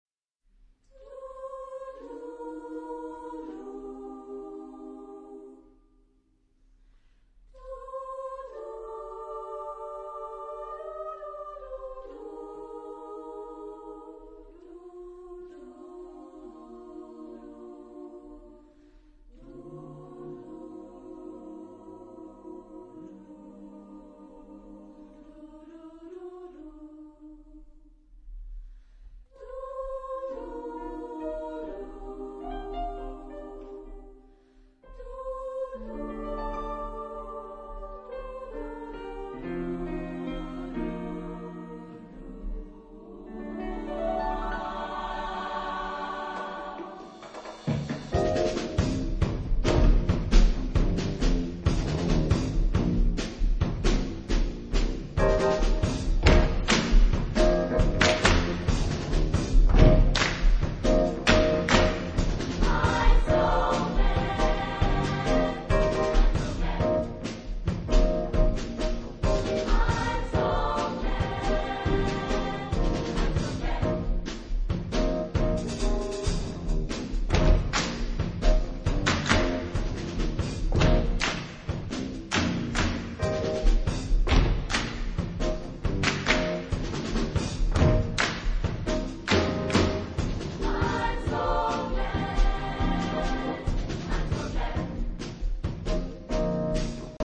Genre-Stil-Form: Gospel ; geistlich
Charakter des Stückes: flott
Chorgattung: SATB  (4 gemischter Chor Stimmen )
Instrumente: Klavier (1)
Tonart(en): F-Dur